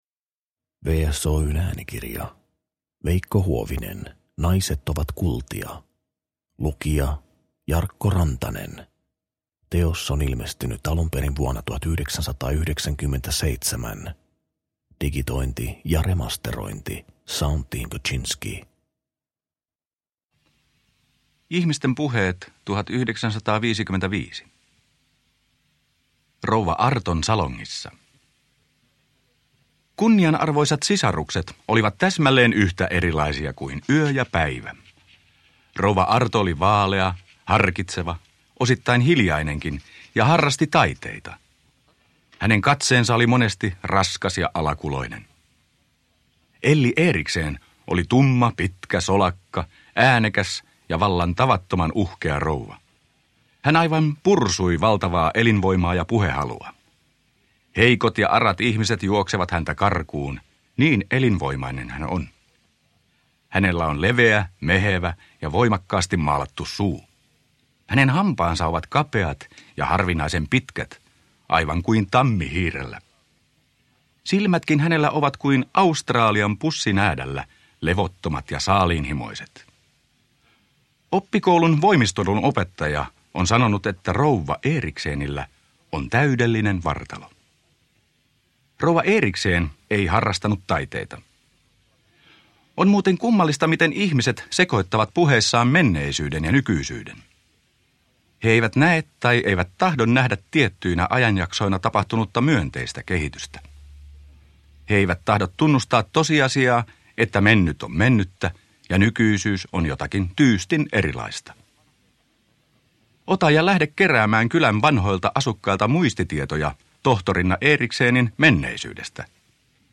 Naiset on kultia – Ljudbok
Uppläsare: